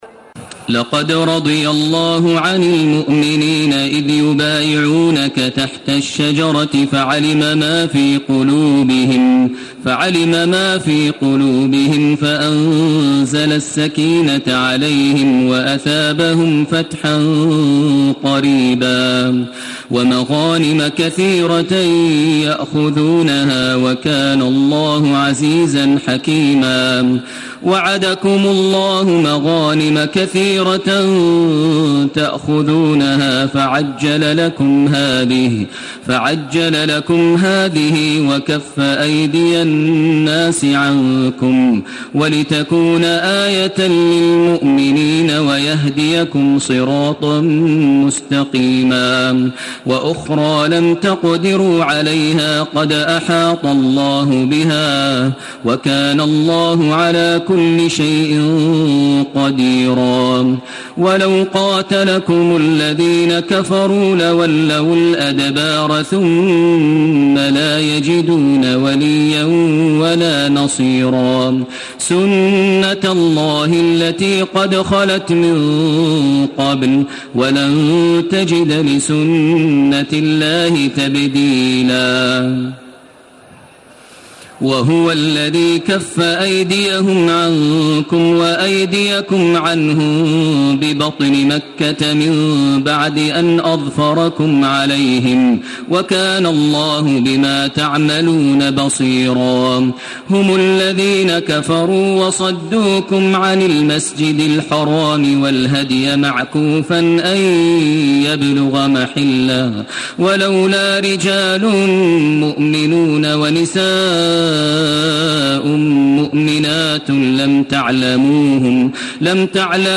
سورة الفتح 16 الي اخرها سورة الحجرات سورة ق سورة الذاريات 1-36 > تراويح ١٤٣٢ > التراويح - تلاوات ماهر المعيقلي